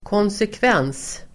Ladda ner uttalet
konsekvens substantiv, consequence Uttal: [kånsekv'en:s] Böjningar: konsekvensen, konsekvenser Synonymer: följd, påföljd Definition: logisk följd Exempel: ta konsekvenserna av sitt beslut (take the consequences of one's decision)